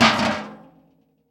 garbage_can.L.wav